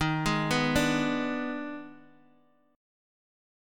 D#M7sus4#5 chord